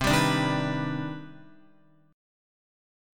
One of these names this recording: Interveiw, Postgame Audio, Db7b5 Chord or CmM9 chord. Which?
CmM9 chord